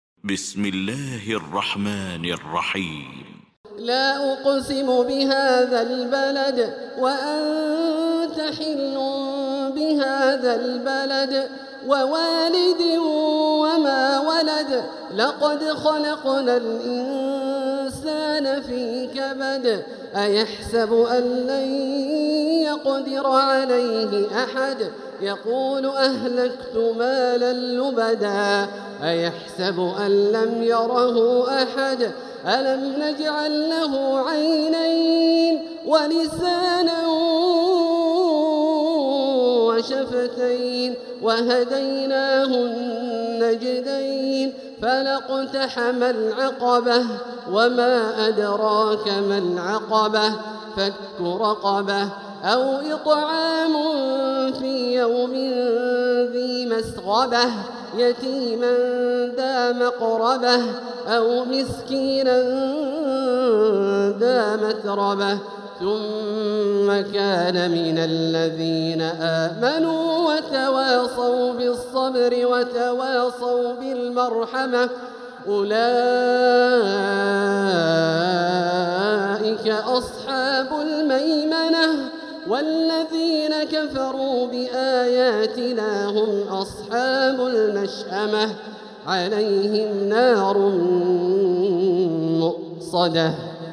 المكان: المسجد الحرام الشيخ: فضيلة الشيخ عبدالله الجهني فضيلة الشيخ عبدالله الجهني البلد